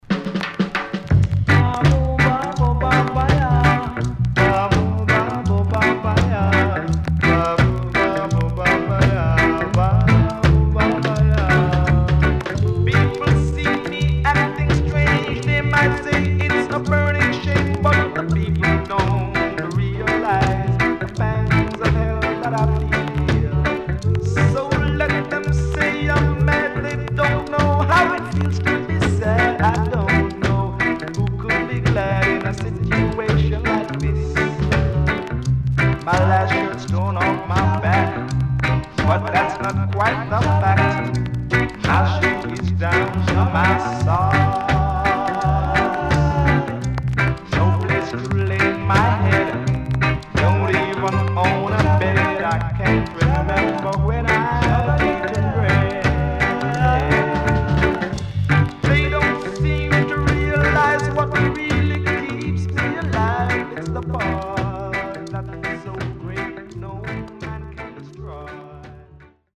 CONDITION SIDE A:VG〜VG(OK)
SIDE A:所々チリノイズがあり、少しプチパチノイズ入ります。